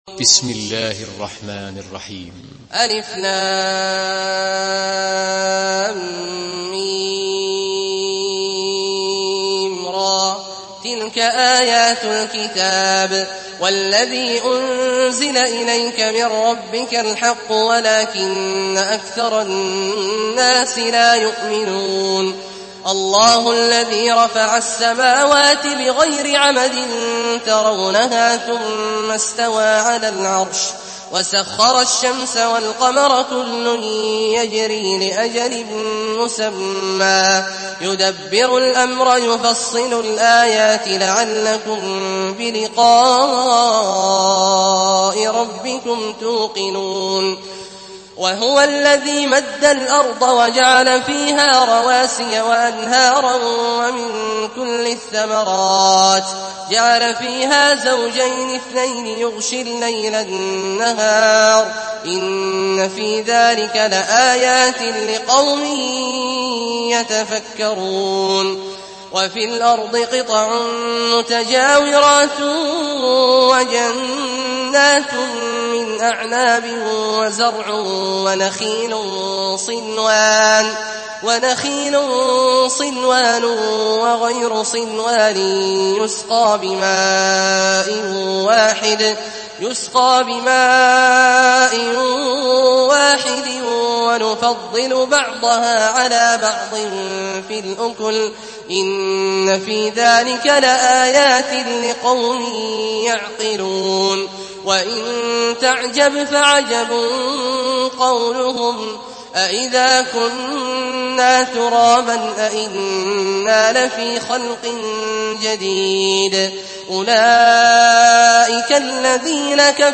Surah Rad MP3 in the Voice of Abdullah Al-Juhani in Hafs Narration
Murattal Hafs An Asim